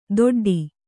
♪ doḍḍi